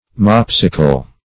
Mopsical \Mop"si*cal\, a. Shortsighted; mope-eyed.